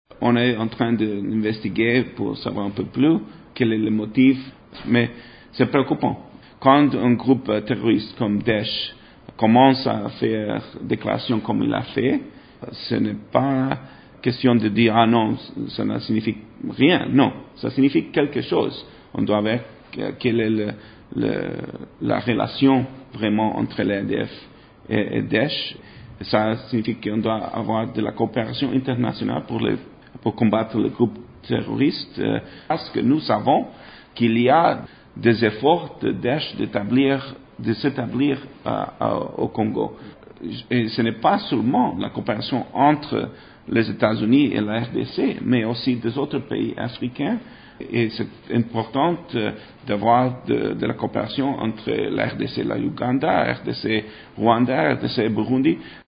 Dans une interview accordée vendredi 3 mai à Radio Okapi à Kinshasa, l’ambassadeur des Etats-Unis en RDC affirme qu’il « suit cette situation avec attention et que les investigations sont en cours pour identifier la présence ou non de ce groupe terroriste » de l’Etat Islamique en RDC.